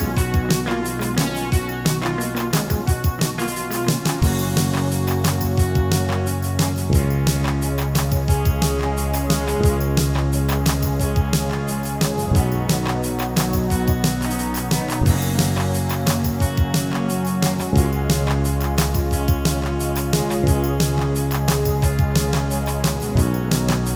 No Lead Guitar Rock 4:38 Buy £1.50